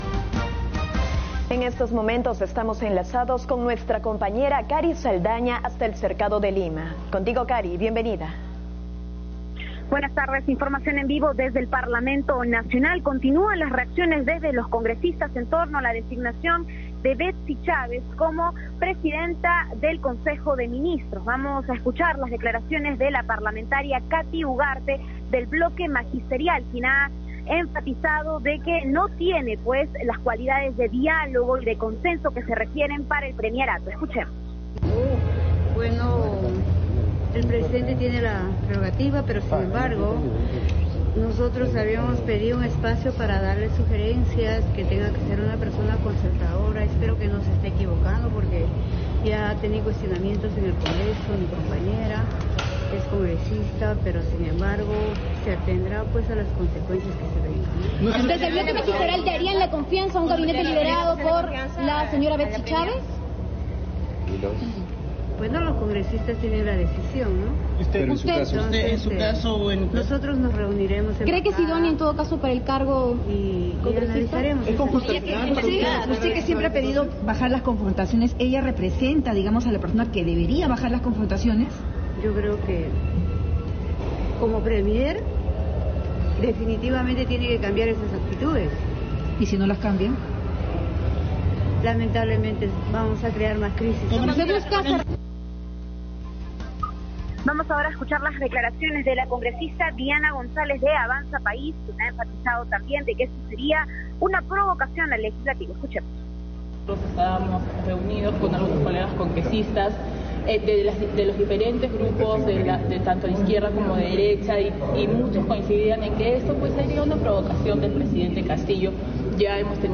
En vivo. Desde el Cercado de Lima, informaron que continúan las reacciones de los congresistas en torno a la designación de Betssy Chávez como presidenta del Consejo de Ministros.